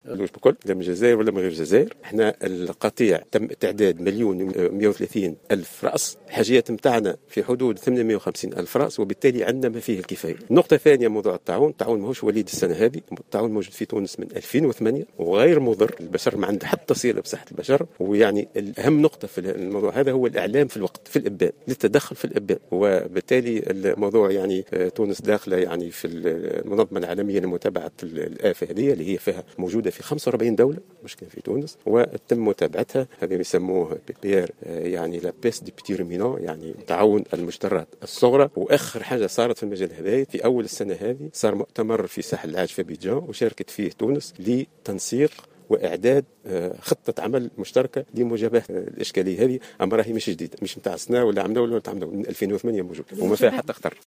وقال في تصريحات لمراسلة "الجوهرة أف أم" إن مثل هذا المرض ليس بالجديد في تونس وقد ظهر منذ 2008، منبها إلى ضرورة الإبلاغ عنه في حال ظهوره والتدخل في الإبان بالقضاء على الخرفان المريضة.